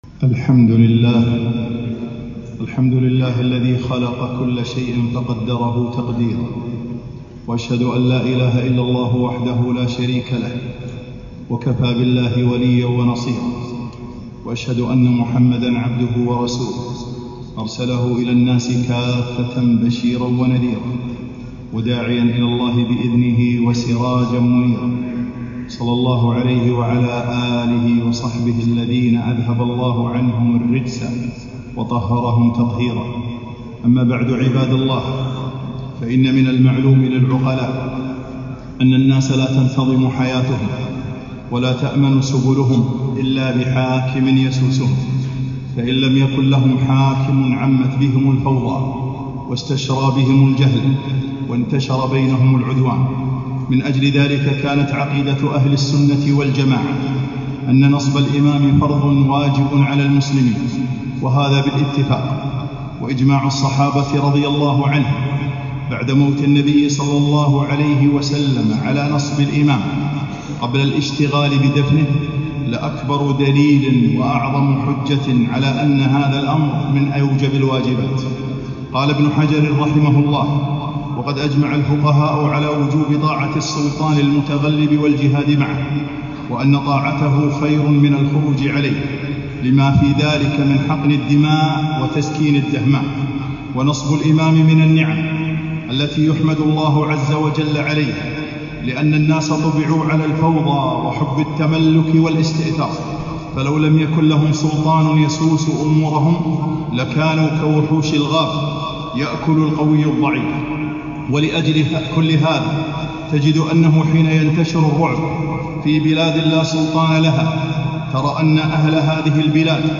خطبة - من حقوق الوالي المسلم